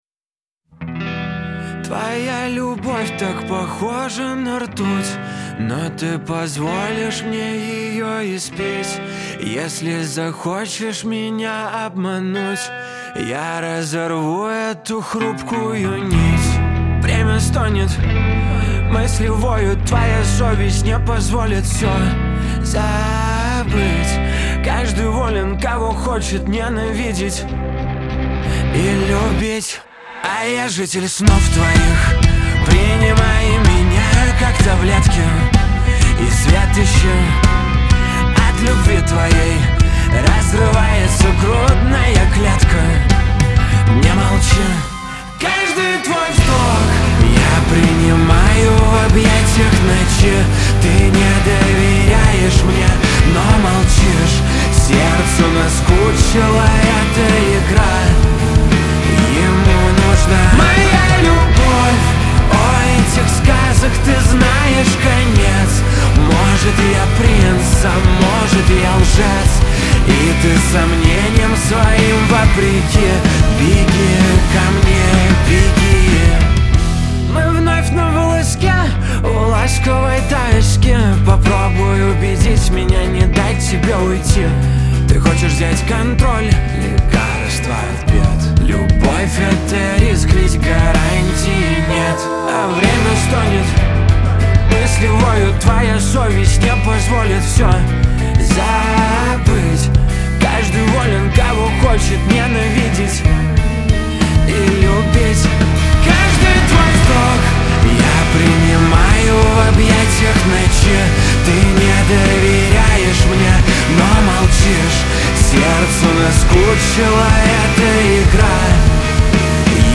pop
эстрада , диско